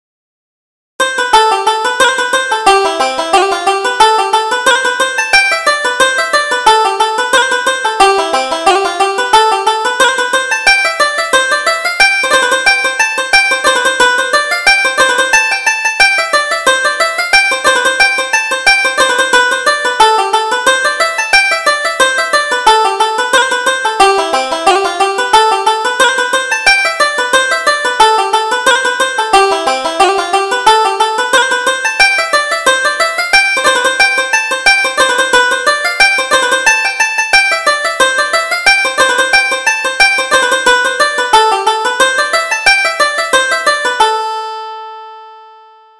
Reel: Ballinasloe Fair